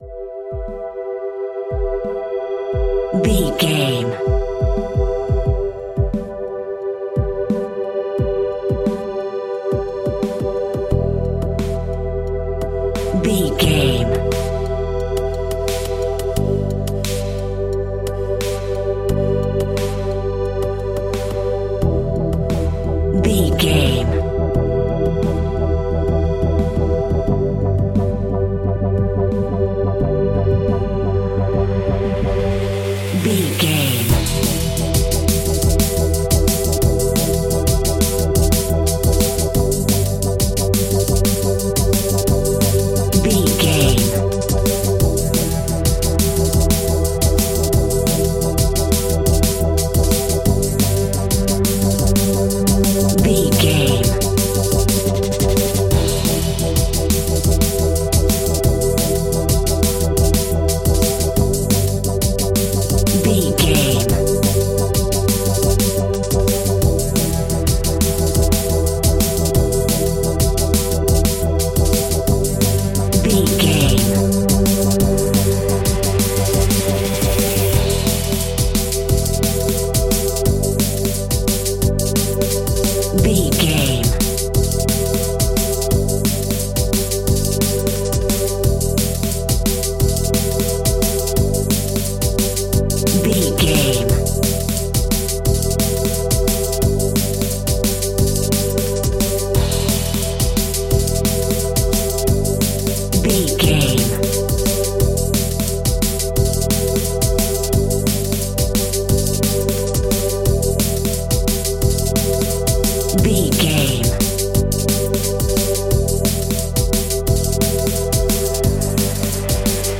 Aeolian/Minor
Fast
aggressive
powerful
uplifting
futuristic
hypnotic
industrial
dreamy
drum machine
synthesiser
break beat
electronic
sub bass
synth leads
synth bass